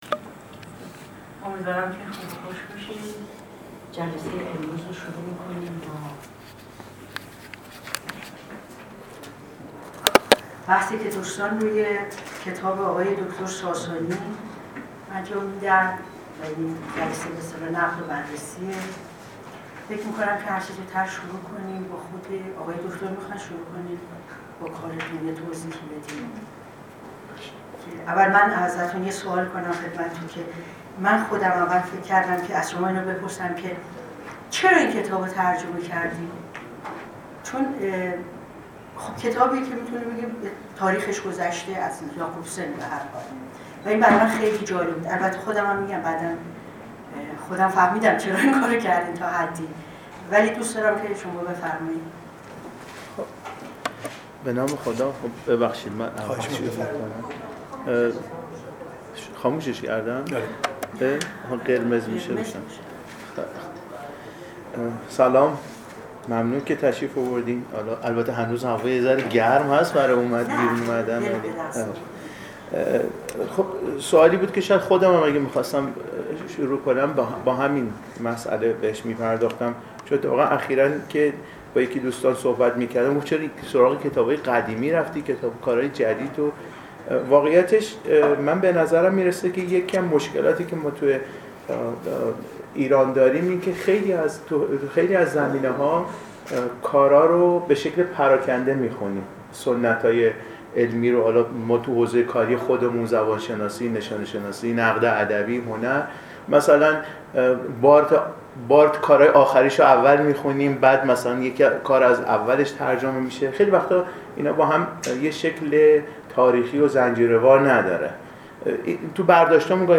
نشست